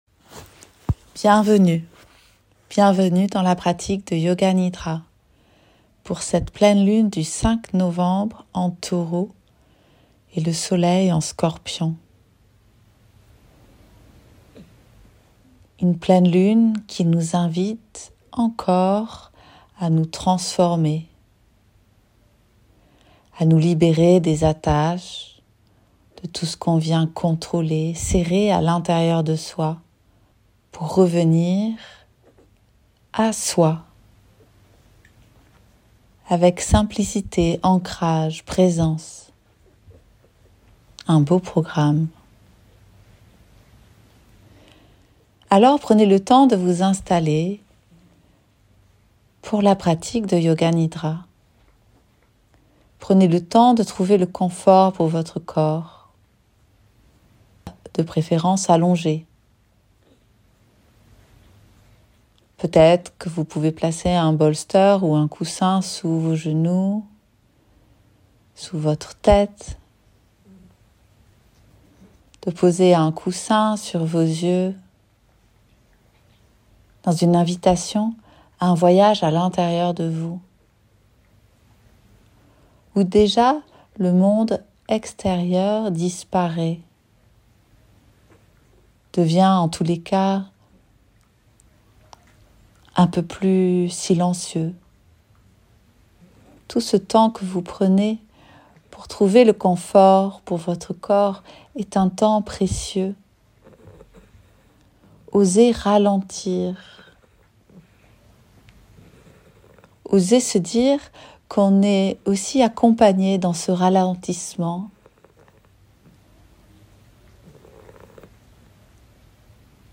Yoga Nidra de la pleine lune du 5 novembre: Ancrage du corps